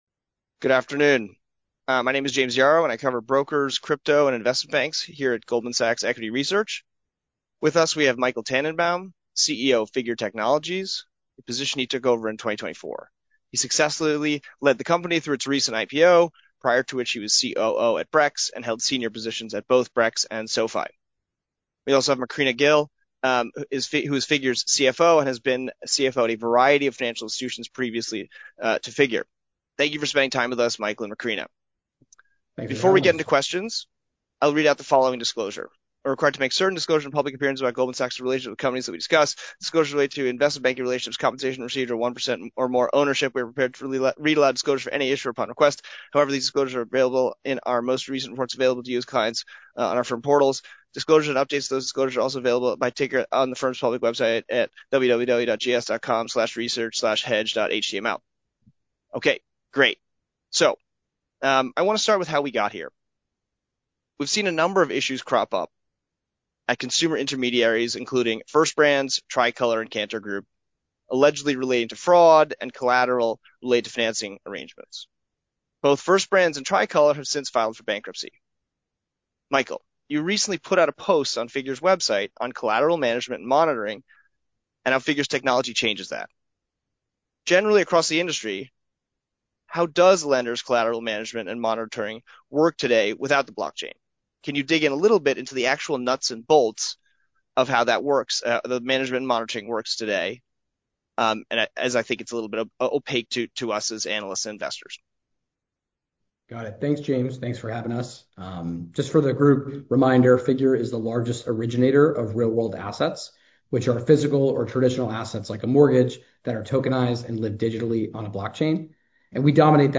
Fireside Chat with Goldman Sachs | Figure Technology Solutions, Inc.